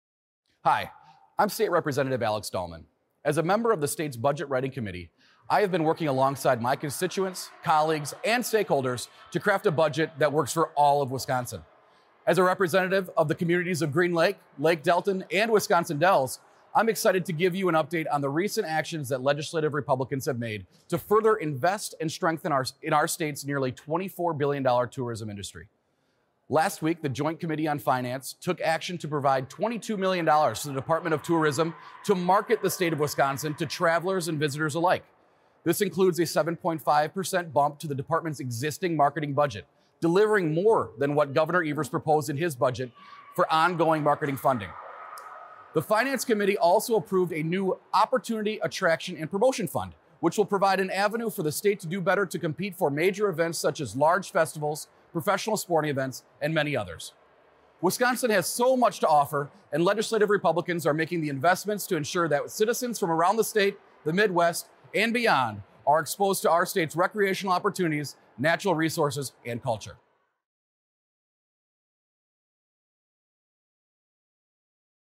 Weekly GOP radio address: Rep. Dallman touts JFC investment in Wisconsin Tourism - WisPolitics
MADISON – Representative Alex Dallman (R – Green Lake) released the weekly radio address on behalf of Wisconsin Legislative Republicans.